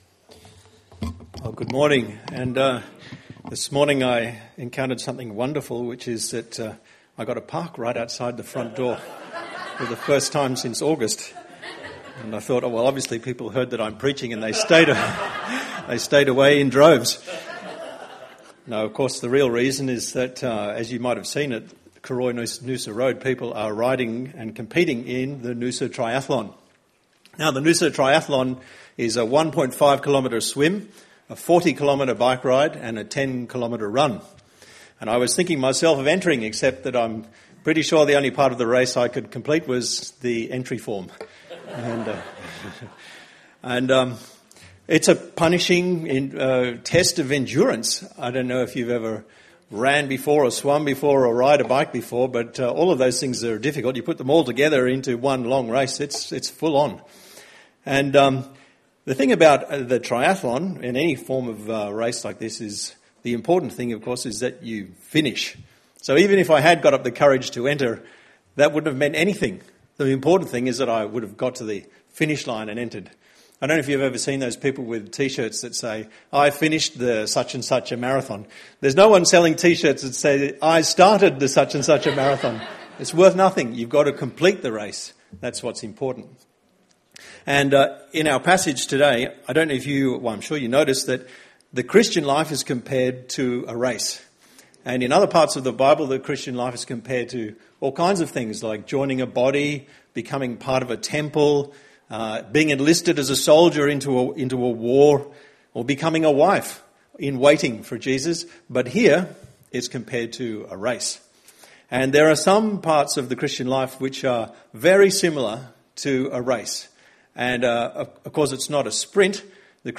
The first sermon in our new series “That’s from the Bible!?” Passage – Hebrews 12:1-3.
Audio recorded at our Tewantin site.